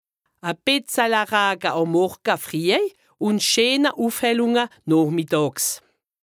Haut Rhin
Ville Prononciation 68